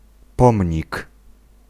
Ääntäminen
US : IPA : [ˈmɑːn.jə.mənt]